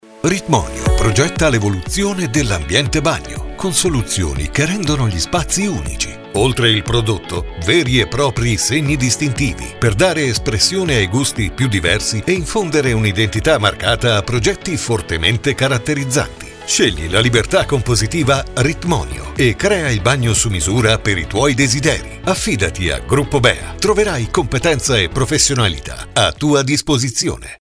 RitmonioSpot.mp3